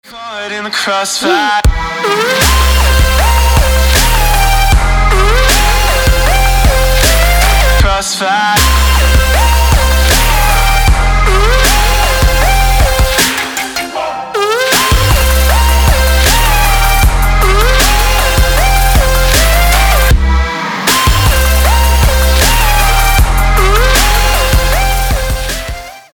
Electronic
Trap
club
electro